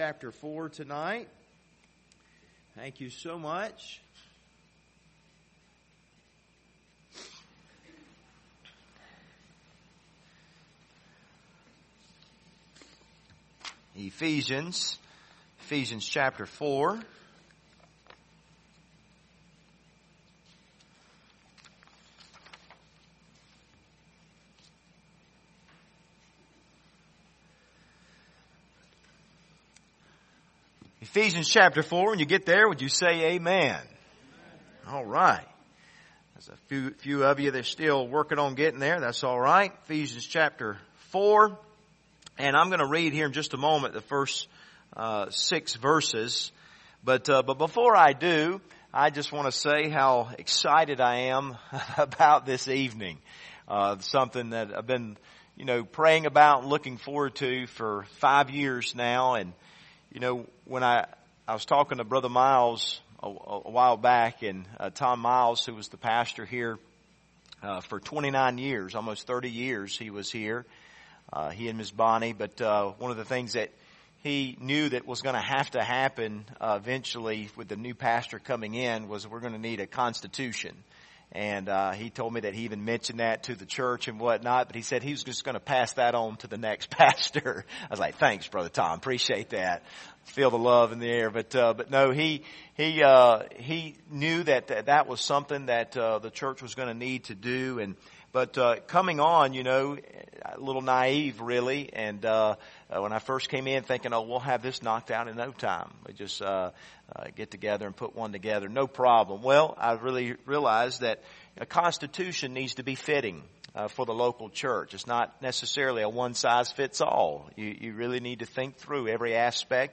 General Passage: Ephesians 4:1-6 Service Type: Sunday Evening Topics